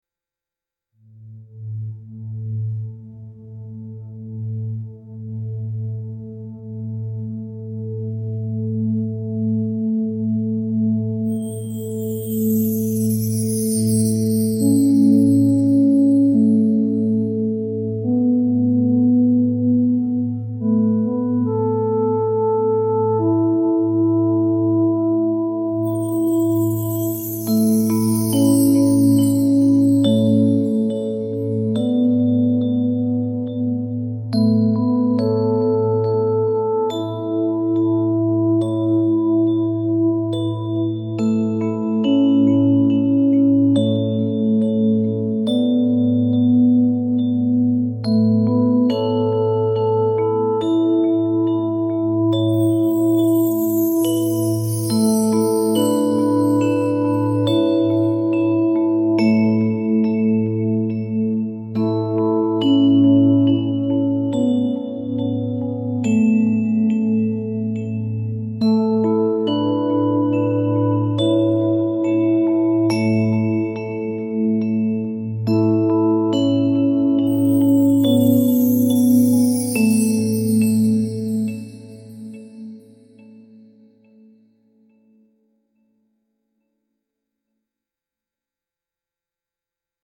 sacred ambient music with organ undertones and peaceful bells